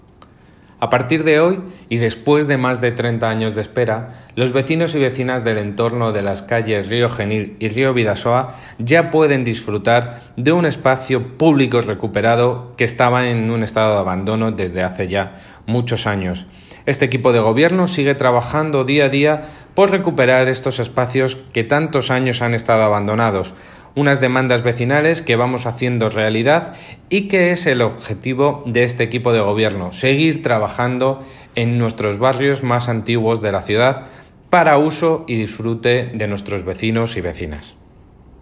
David Muñoz - Concejal de Mejora y Mantenimiento de los Espacios Públicos